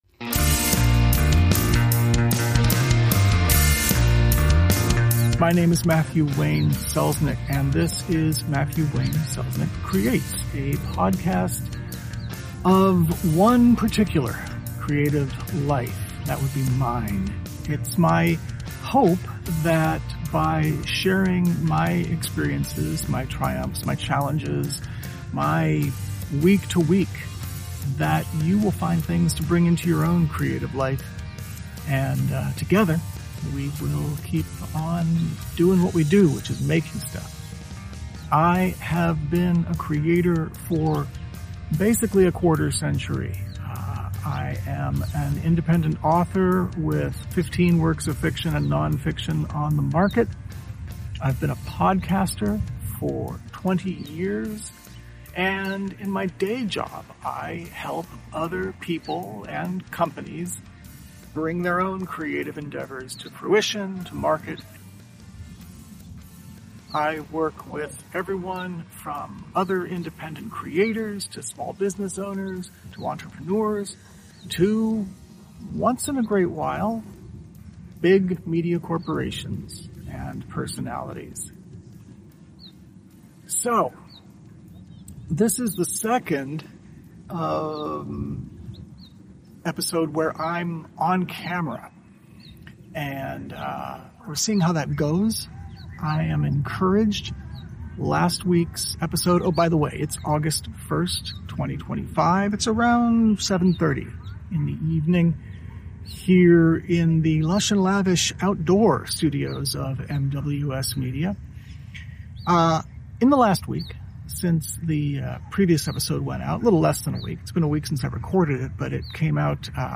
This episode was recorded (audio and video) using a Pixel 9a phone with no external tools or third-party apps.